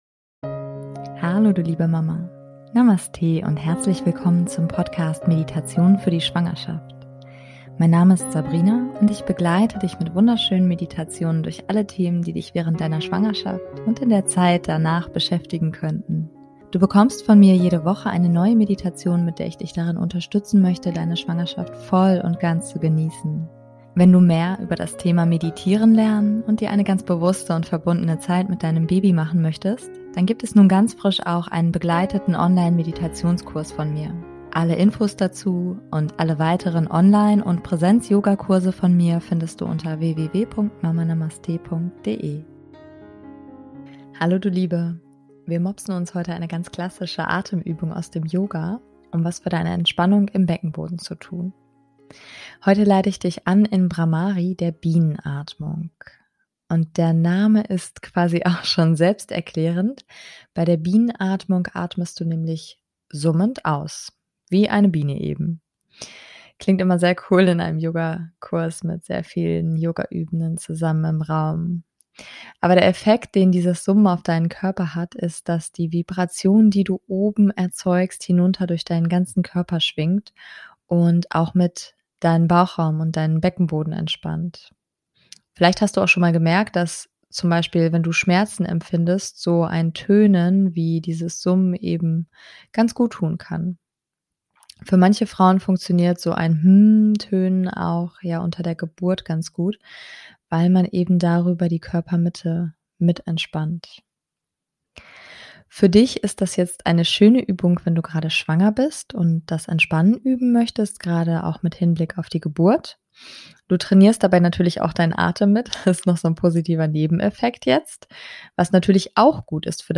Wir mopsen uns heute eine ganz klassische Atemübung aus dem Yoga, um was für die Entspannung im Beckenboden zu tun. Heute leite ich dich an in Brahmari, der Bienenatmung.
Bei der Bienenatmung atmest du nämlich summend aus, wie eine Biene eben.